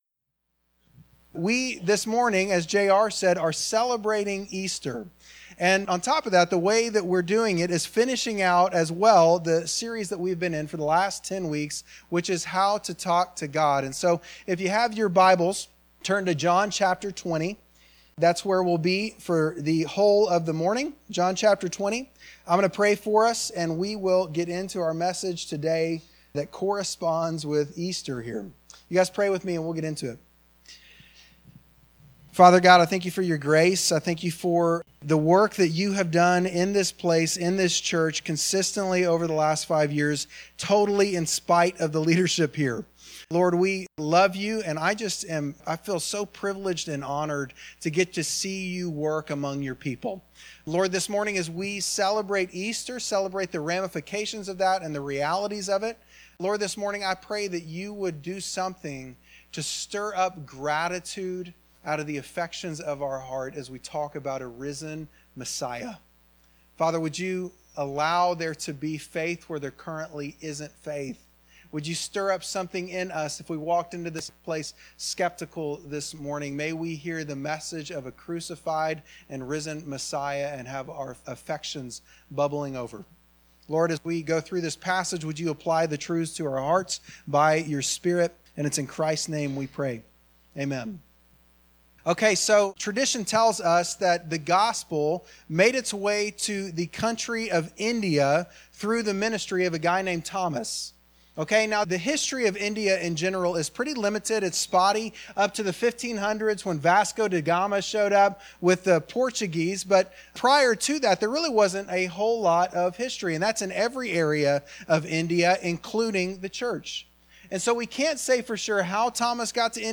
Bible Text: John 20:24-29 | Preacher